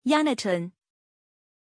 Pronunciación de Yonatan
pronunciation-yonatan-zh.mp3